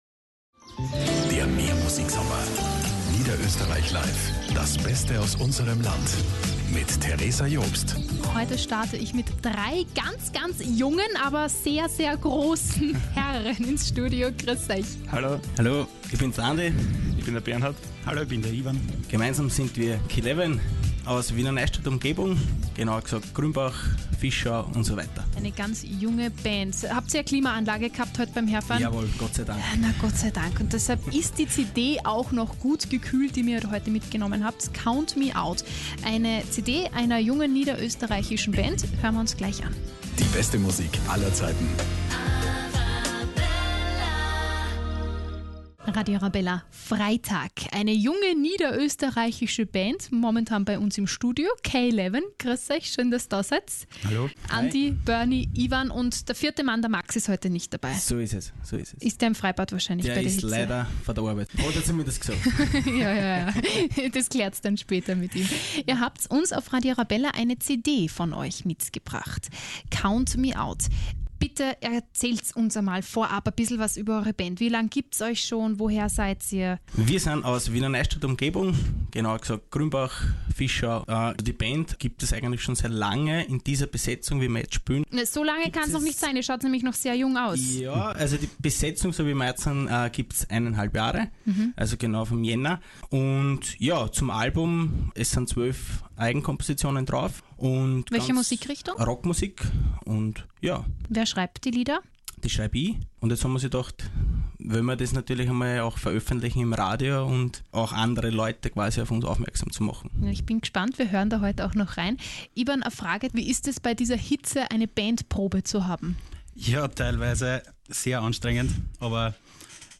Keleven zu Gast bei Radio Arabella!
Radio-Arabella-Mitschnitt.mp3